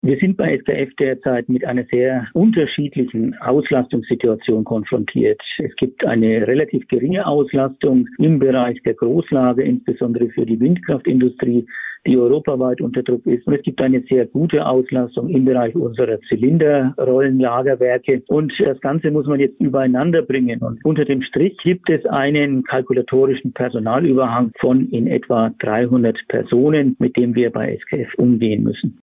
Interview: Großer Stellenabbau bei SKF in Schweinfurt - PRIMATON